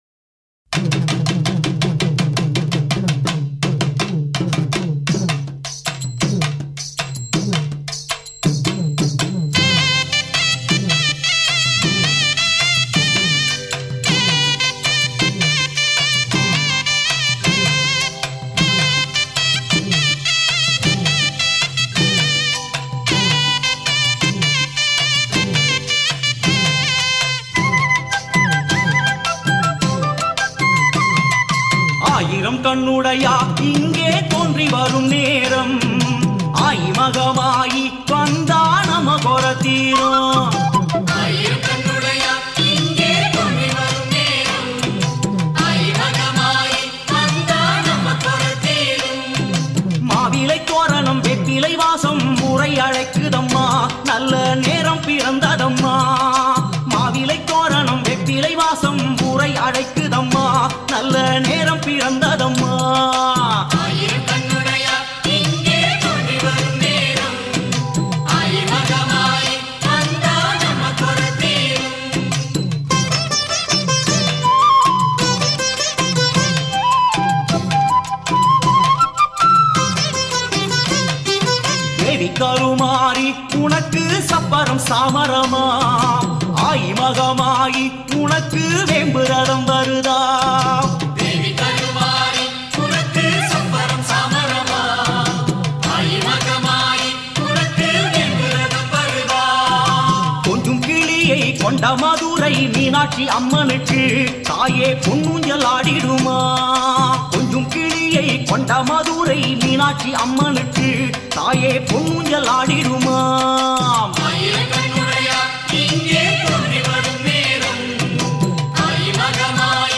amman song